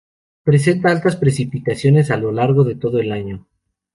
lar‧go
Pronúnciase como (IPA)
/ˈlaɾɡo/